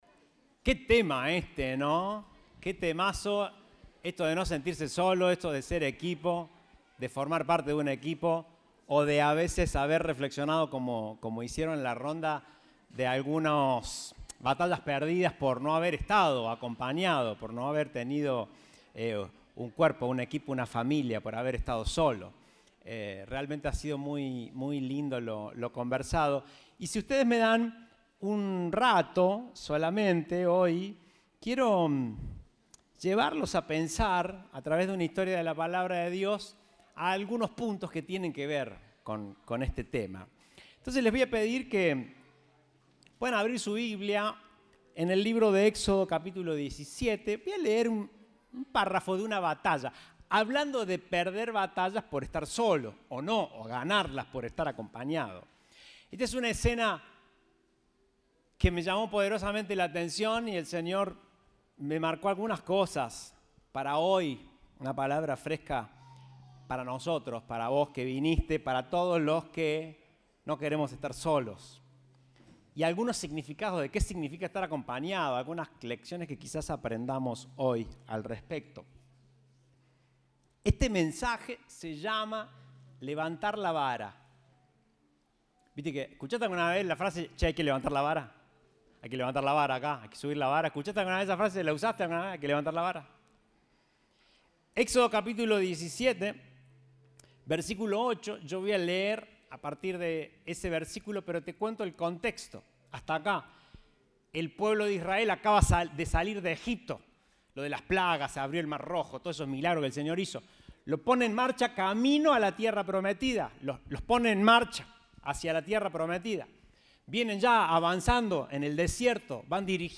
Compartimos el mensaje del Domingo 13 de Marzo de 2022.